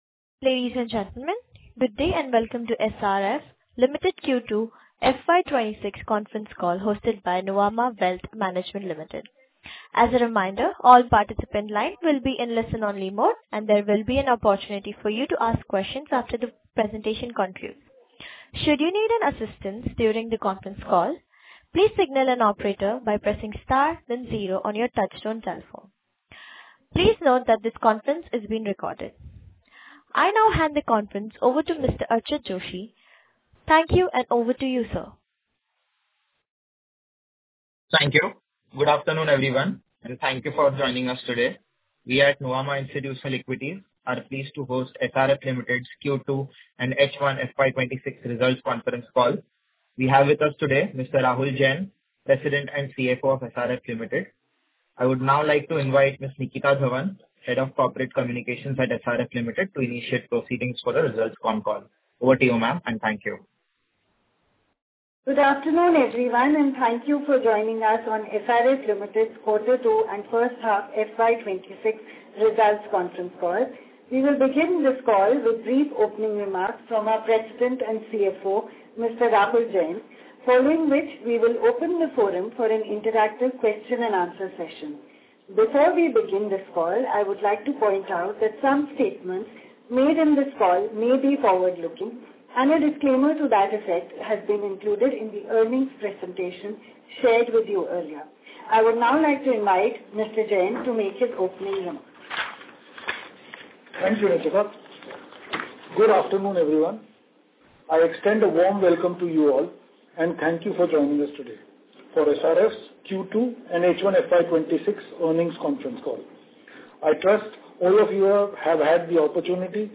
SRF-Q2-H1-FY26-Earning-Call-Auido.mp3